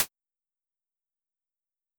Effects_r1_Click.wav